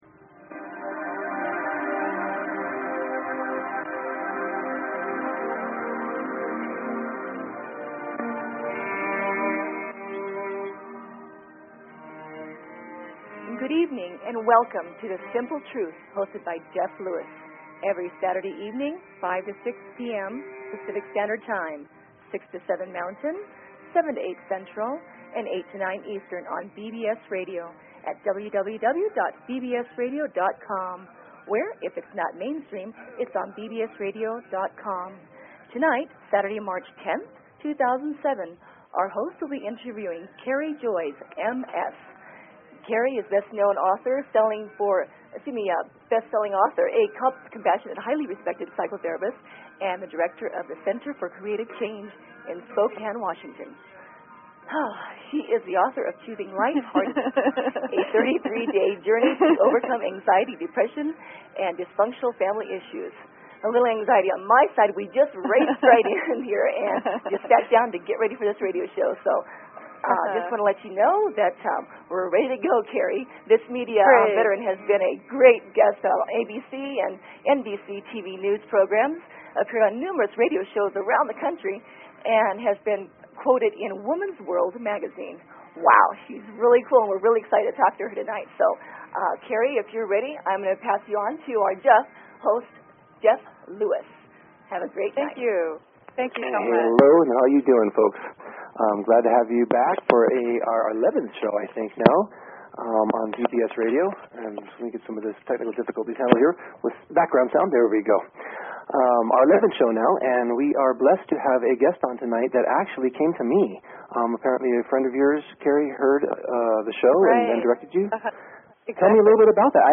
Talk Show Episode, Audio Podcast, The_Simple_Truth and Courtesy of BBS Radio on , show guests , about , categorized as